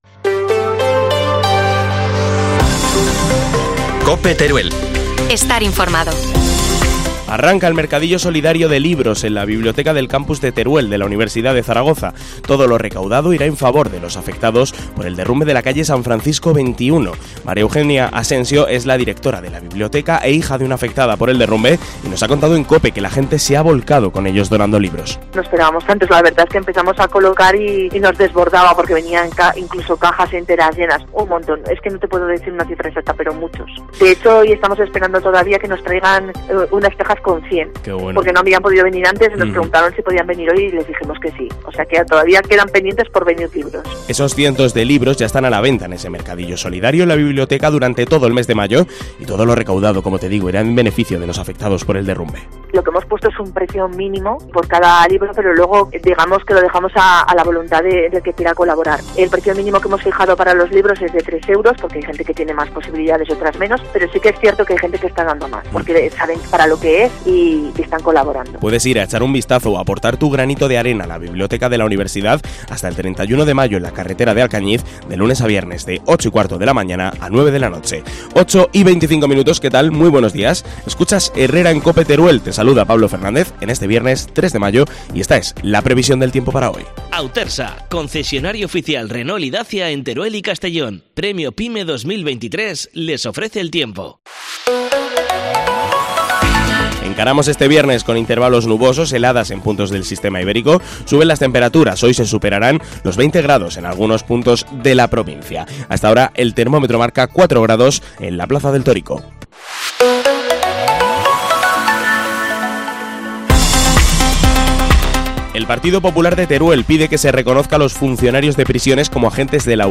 AUDIO: Titrulares del díua en COPE Teruel